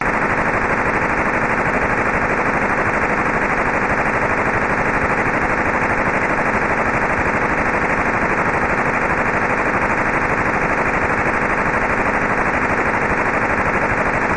NXDN96.mp3